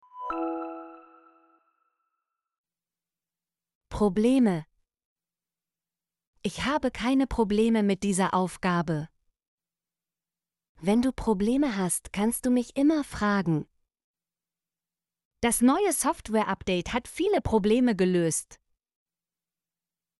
probleme - Example Sentences & Pronunciation, German Frequency List